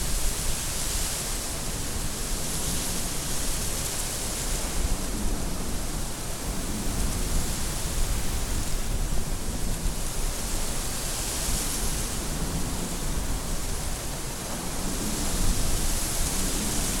Ambiance Ouragan (Broadcast) – Le Studio JeeeP Prod
Bruits d’ambiance d’un ouragan en cours.
Ambiance-Ouragan.mp3